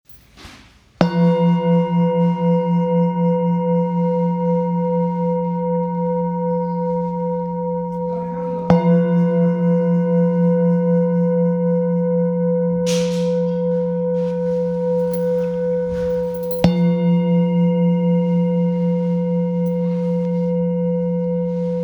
Singing Bowl, Buddhist Hand Beaten, with Fine Etching Carvin of Om, Select Accessories
Material Seven Bronze Metal